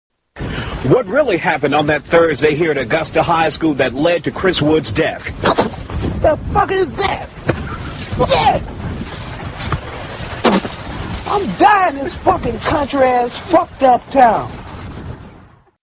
Tags: News Anchor Fail News Anchor Fail clips News fail Reporter fail News fail clips